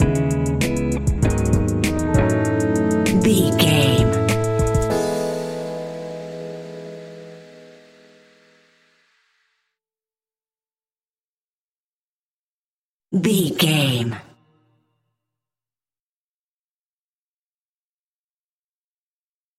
Ionian/Major
instrumentals
chilled
laid back
hip hop drums
hip hop synths
piano
hip hop pads